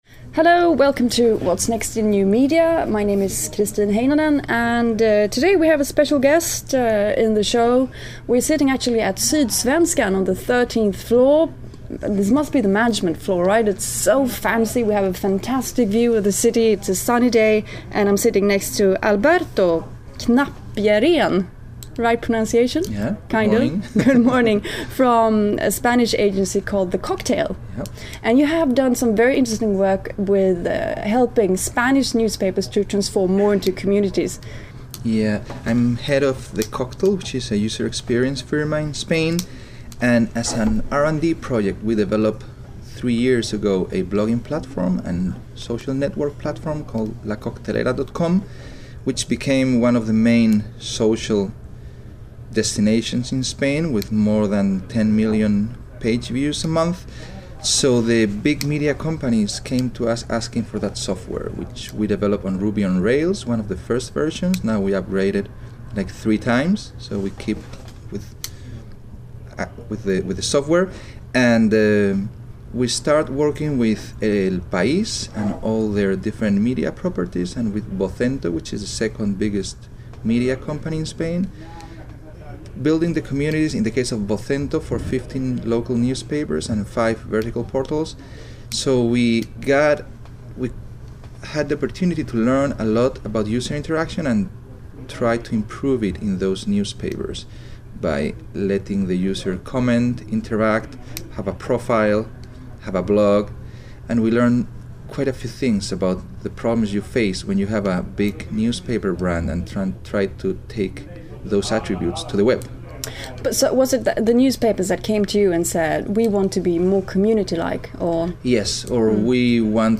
Efteråt frukosten fick What’s Next in New Media en pratstund